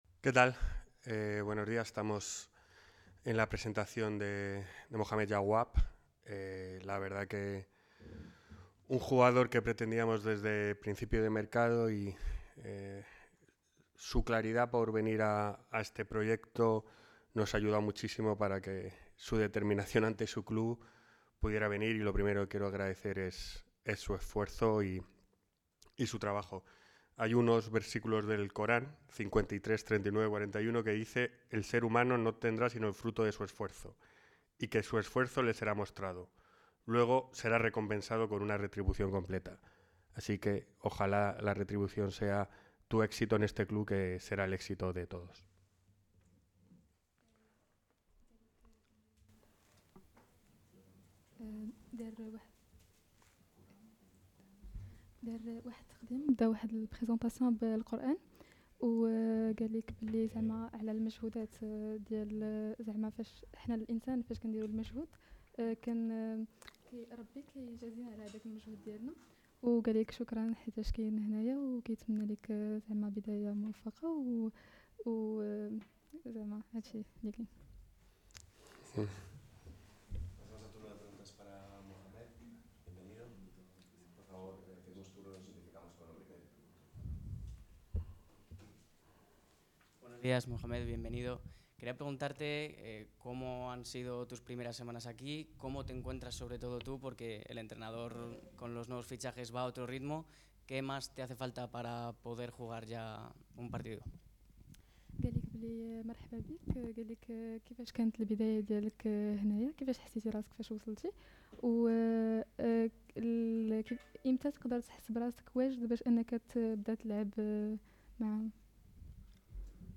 rueda de prensa asistida por una traductora